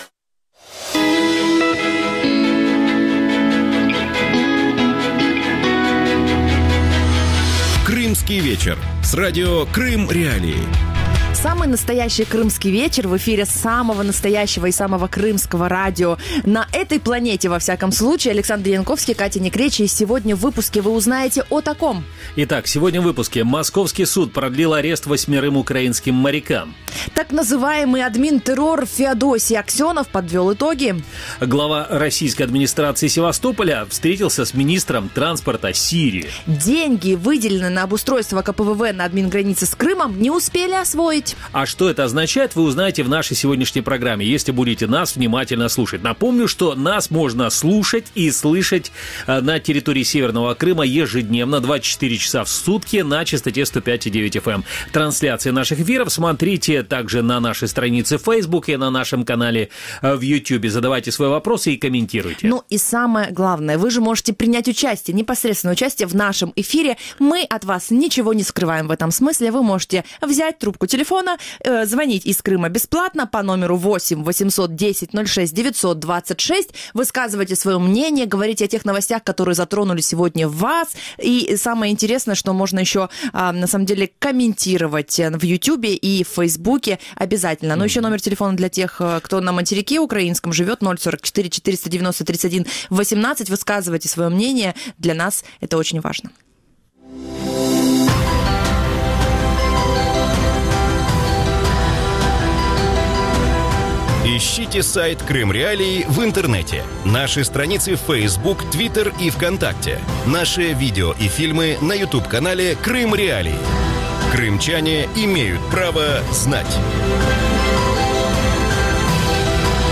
Гости эфира: российский адвокат